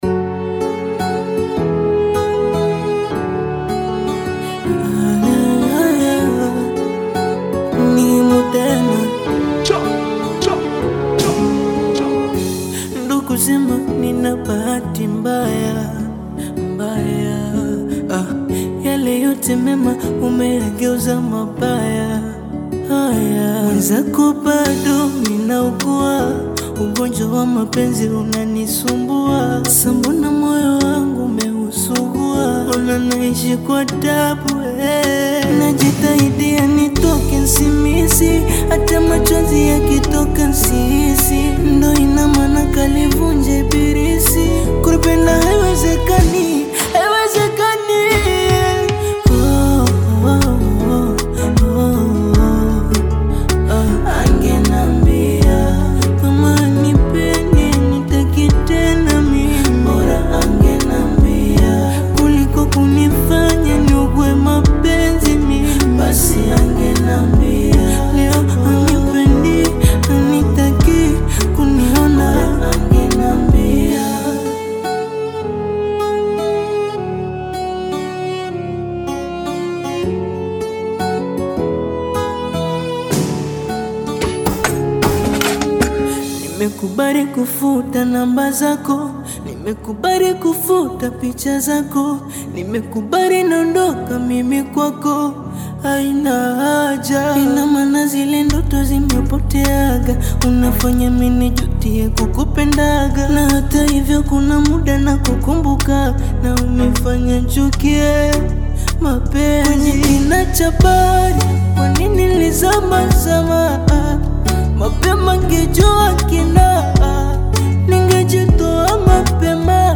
Tanzanian Bongo Flava artist, singer and songwriter